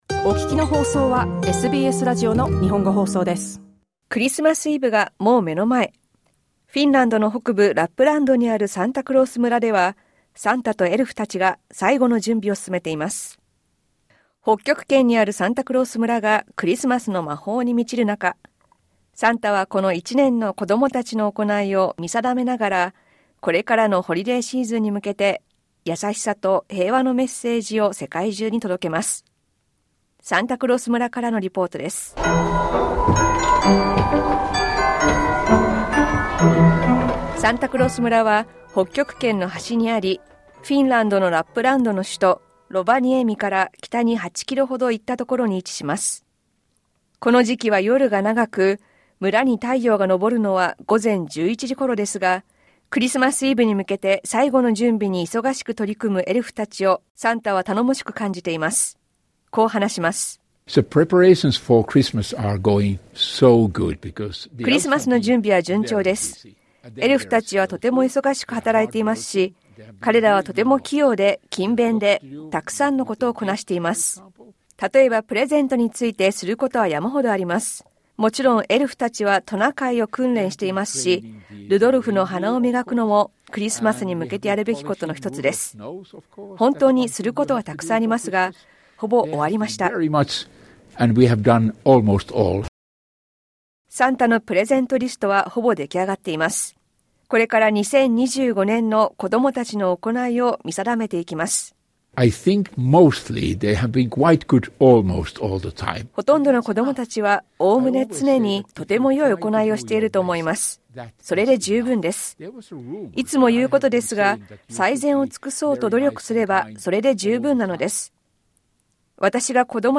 フィンランドの北部ラップランドにあるサンタクロース村では、サンタとエルフたちが最後の準備を忙しく進めています。サンタクロース村からのリポートです。